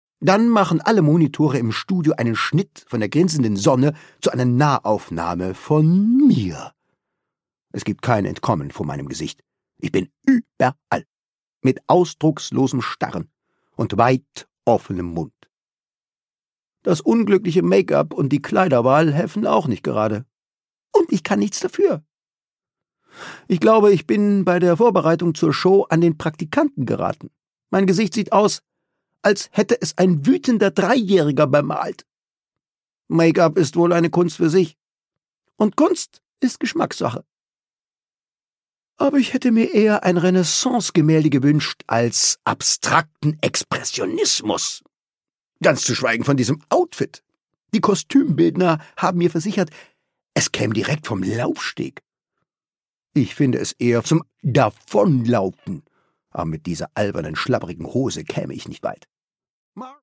Produkttyp: Hörbuch-Download